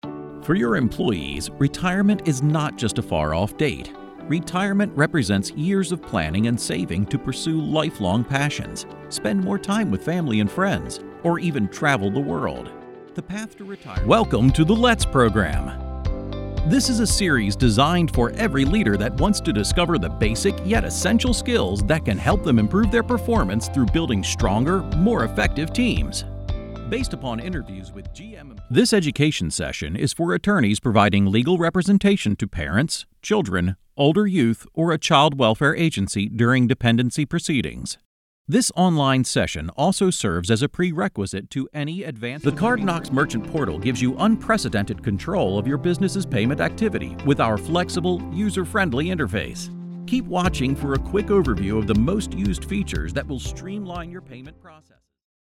Male
Adult (30-50), Older Sound (50+)
Explainer Narration
Words that describe my voice are dynamic, articulate, versatile.
0712Explainer_Demo.mp3